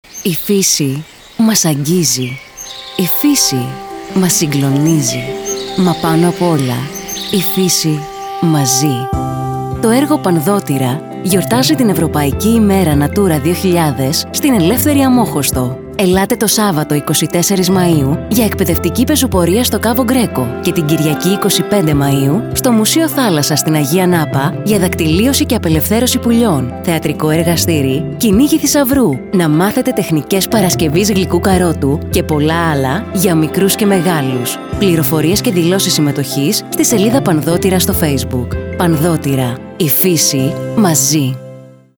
Τηλεοπτικά και ραδιοφωνικά σποτ